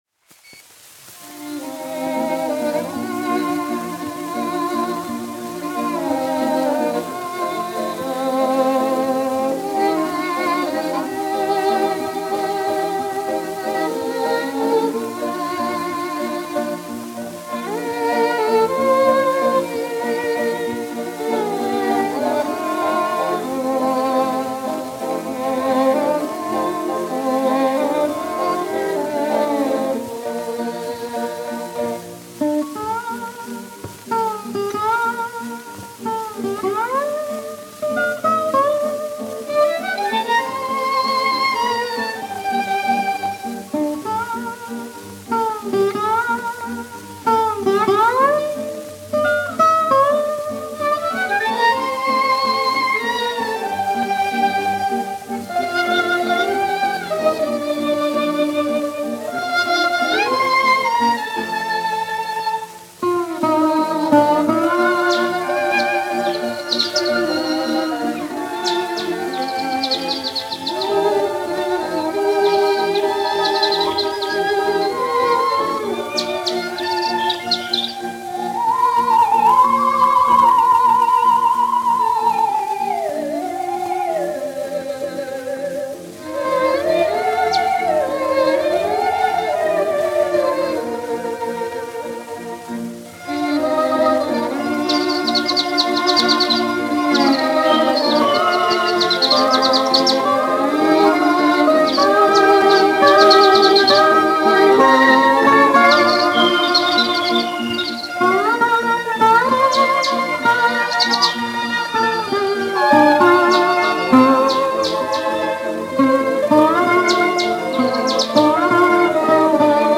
1 skpl. : analogs, 78 apgr/min, mono ; 25 cm
Skaņuplate